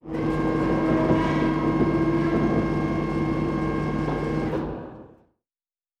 Servo Big 3_2.wav